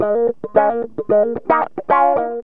GTR 11A#M110.wav